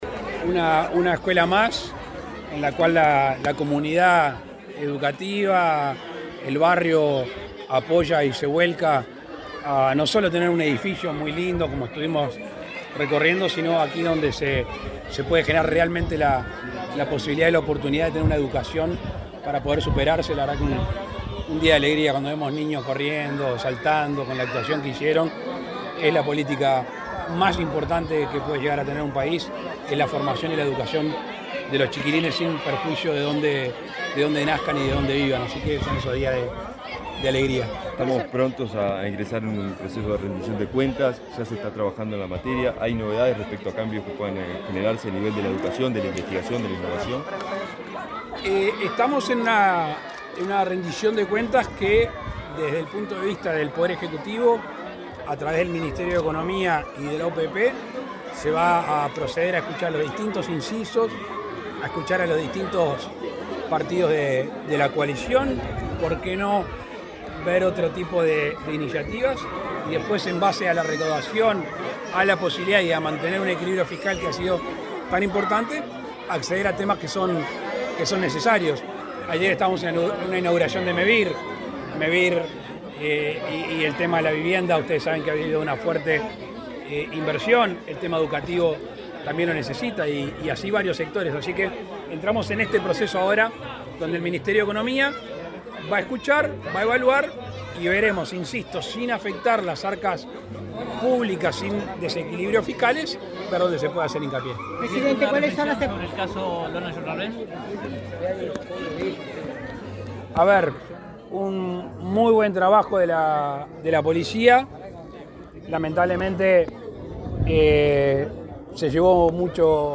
Declaraciones del presidente Lacalle Pou a la prensa